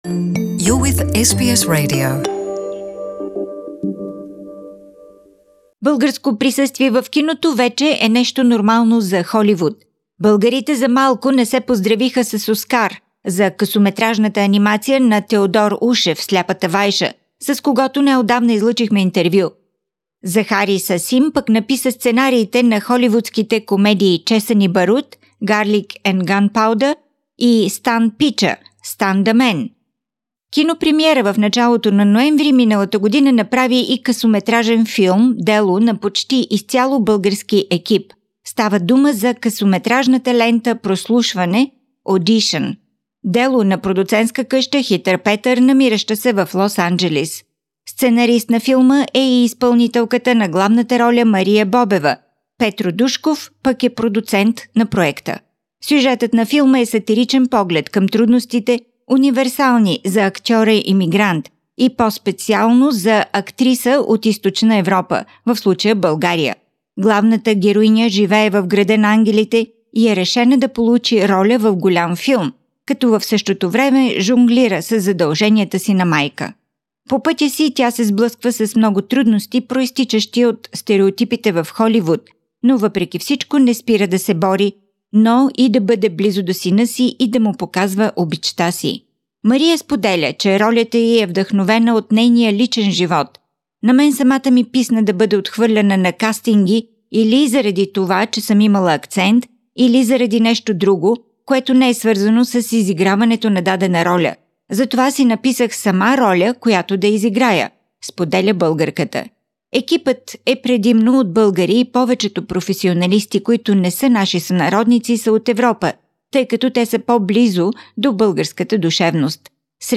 interview A satirical comedy-drama about a Bulgarian immigrant actress in her late 30s struggling to achieve a balance between following her lifelong dedication to acting and being a good mother and role model to her observant ten-year-old son.